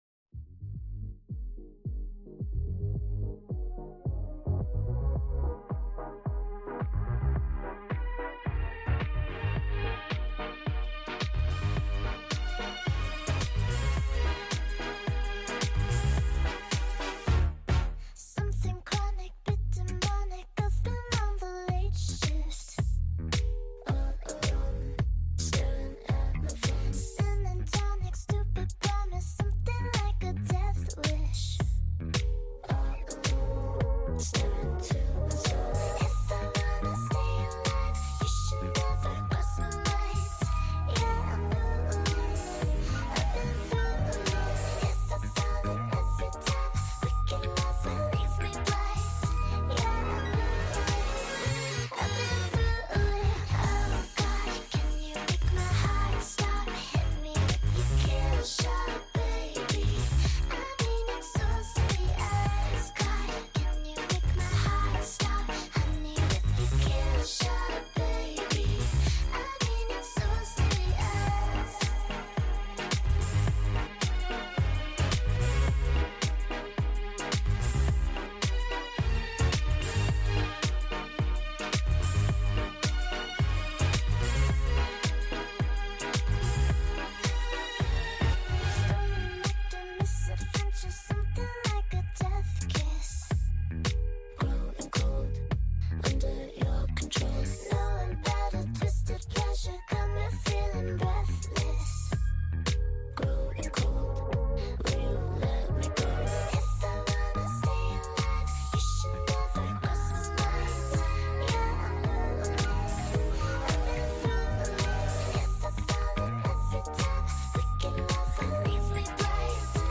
Sped Up version